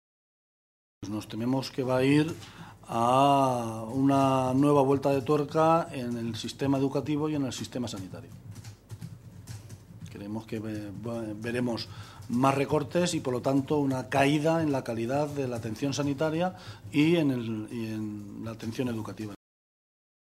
El portavoz del PSOE en el Parlamento regional se pronunciaba de esta manera esta mañana, en Toledo, en una comparecencia ante los medios de comunicación en la que detallaba las cifras que, hasta ahora, ha remitido el Ejecutivo de Cospedal al PSOE.
Cortes de audio de la rueda de prensa